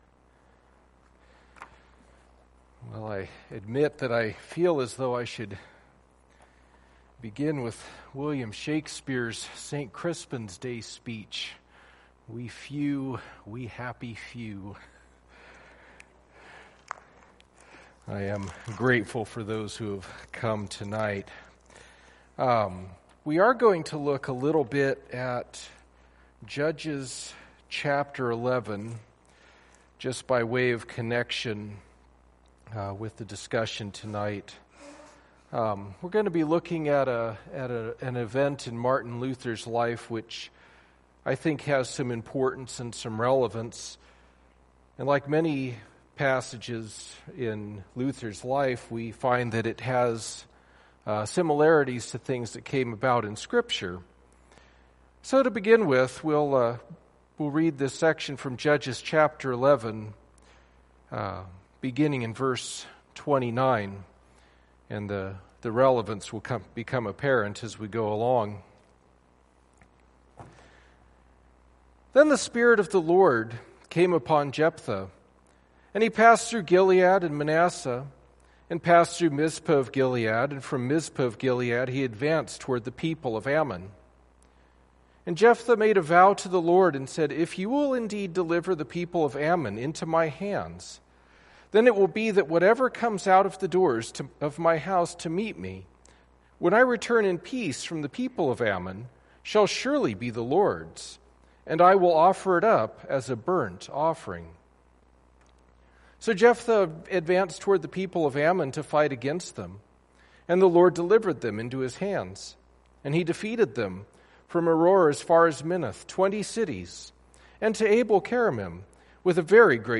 Judges 11:29-40 Service Type: Sunday Evening Topics